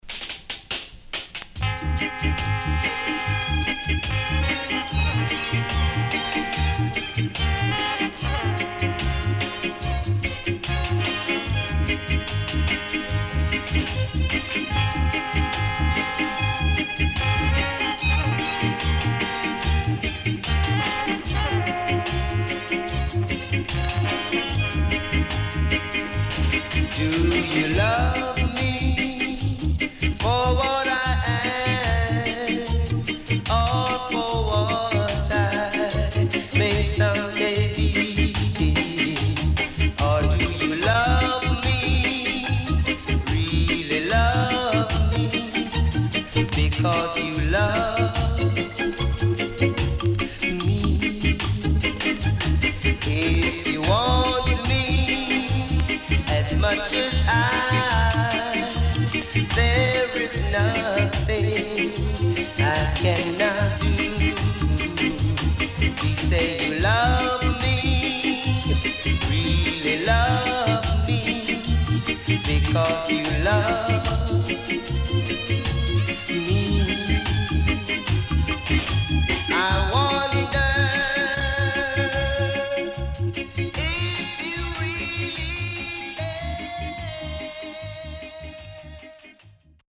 CONDITION SIDE A:VG+
SIDE A:薄いヒスノイズ入りますが良好です。